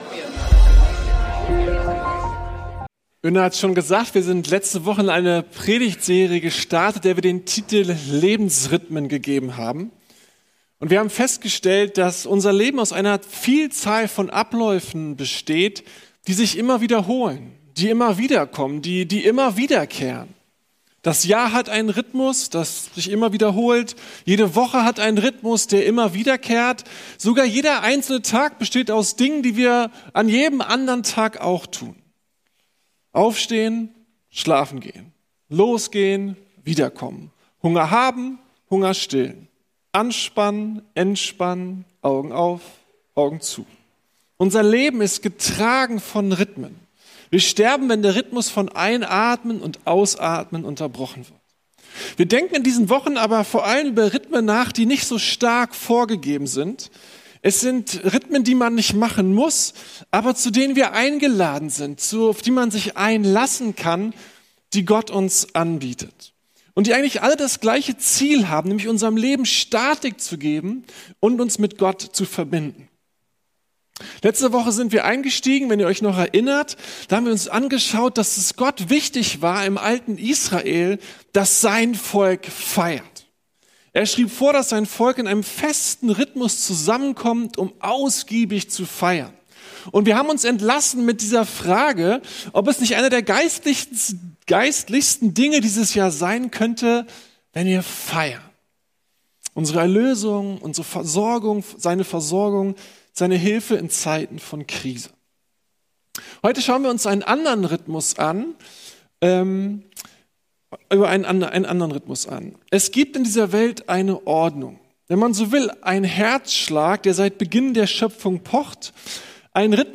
Lebensrhythmen: Ruhen ~ Predigten der LUKAS GEMEINDE Podcast